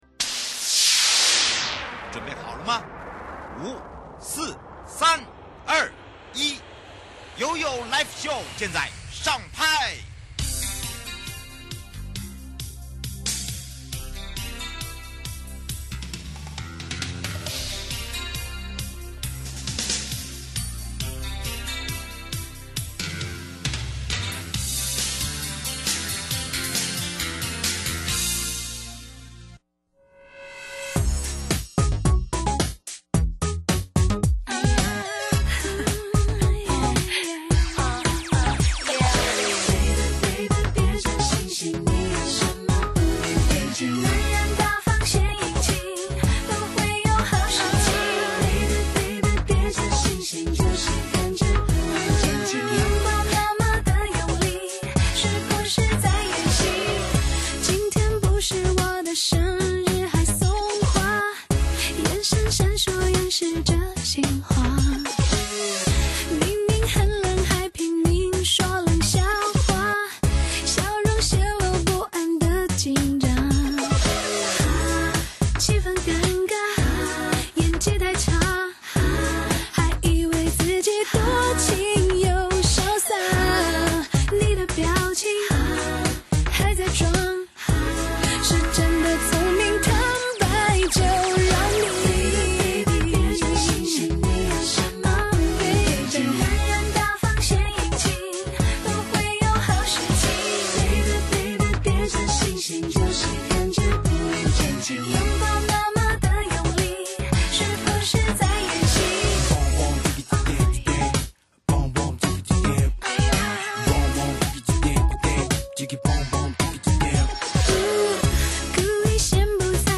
受訪者： 臺北地檢署姜長志檢察官 節目內容： 1.